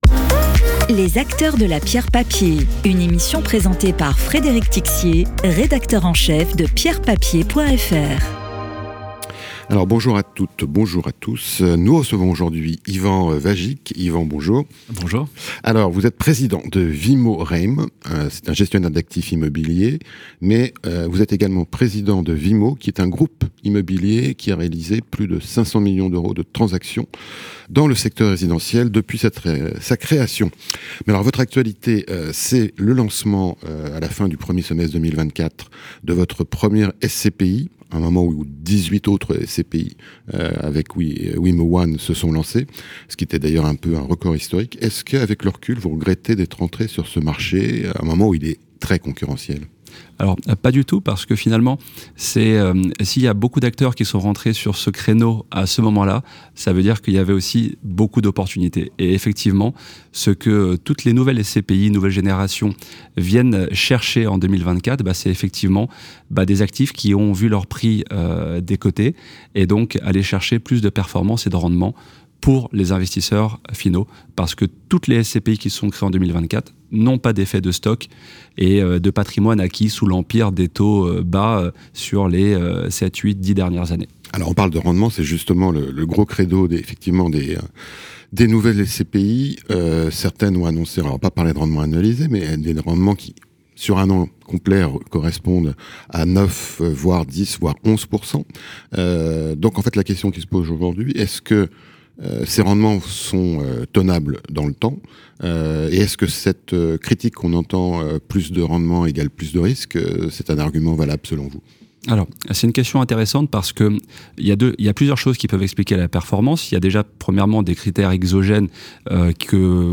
Podcast d'expert